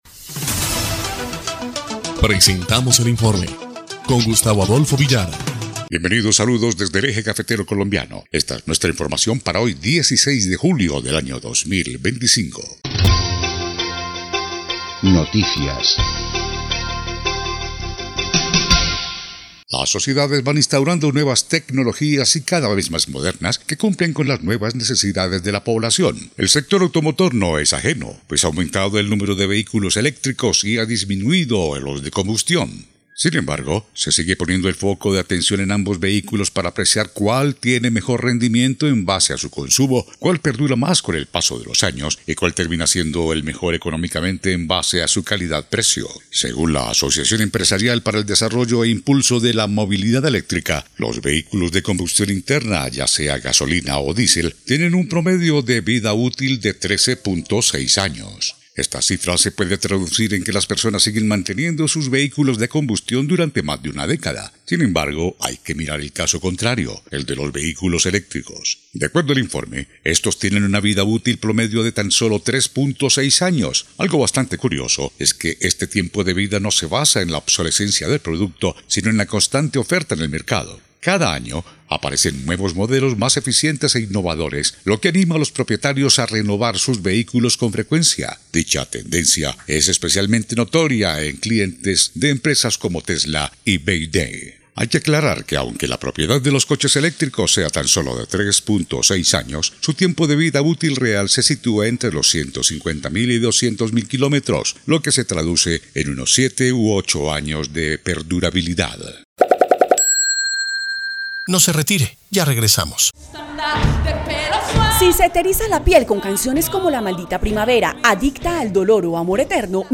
EL INFORME 2° Clip de Noticias del 16 de julio de 2025